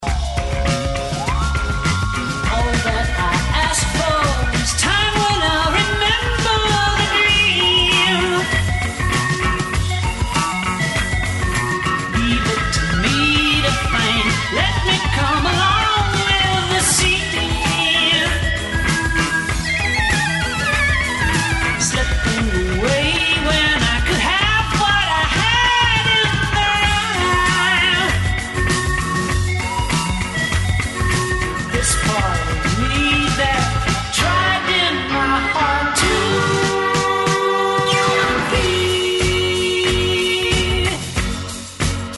Recorded at Hérouville & Ramport Studios, London